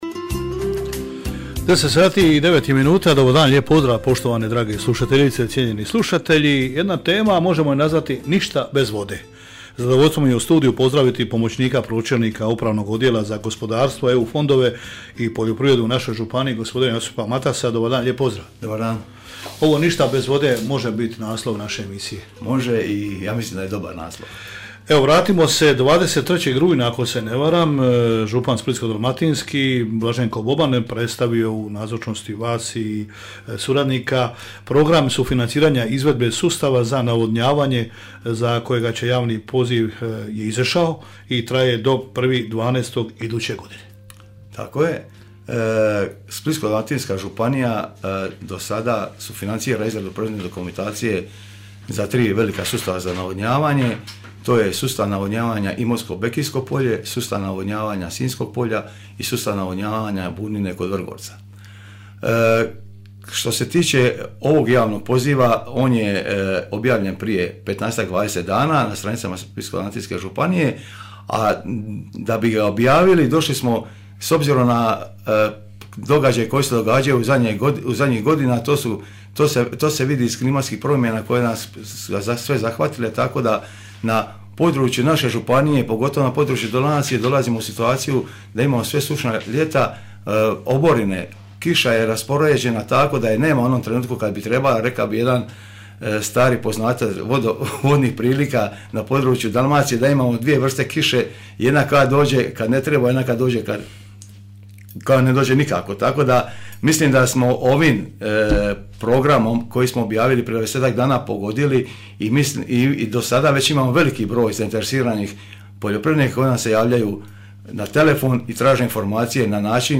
gostujući u programu Hit radija